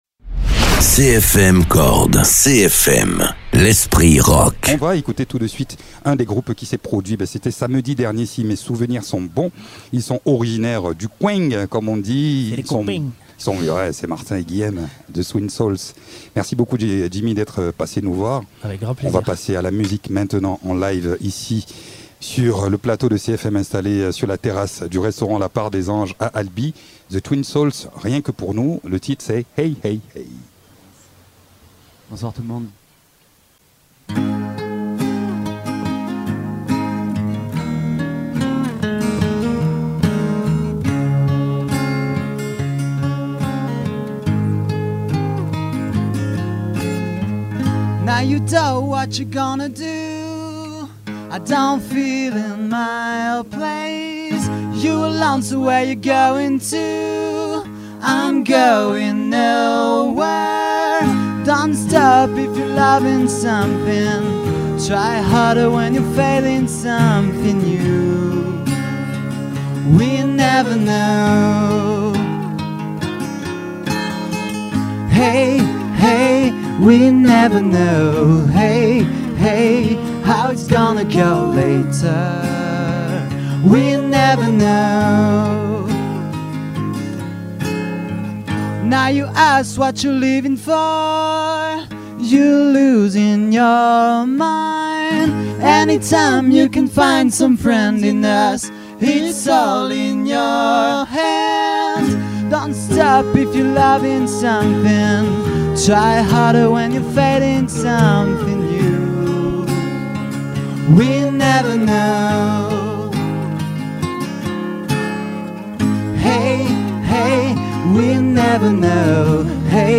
Ils ont joué sur la scène de pollux pour les premiers jours du festival et ce duo de rock originaire du Tarn, nous fait le plaisir de revenir pour un entretien sur nos ondes et 2 titres en live.